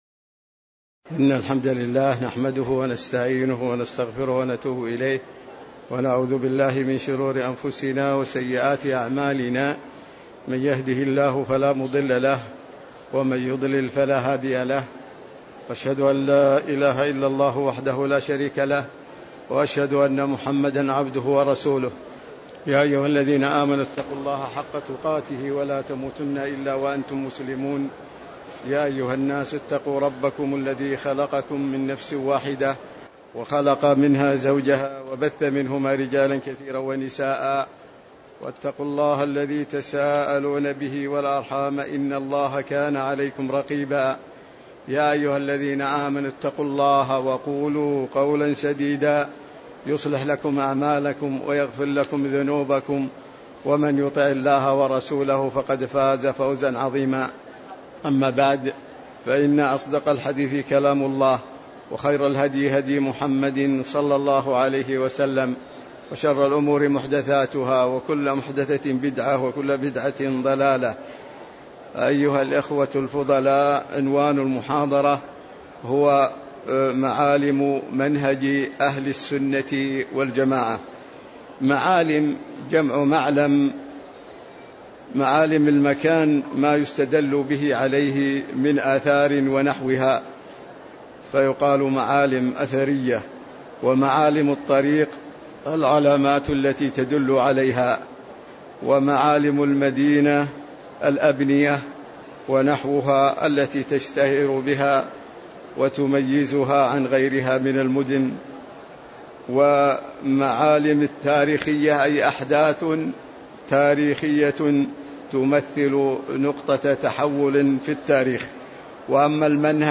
تاريخ النشر ٢٦ ربيع الأول ١٤٤٥ هـ المكان: المسجد النبوي الشيخ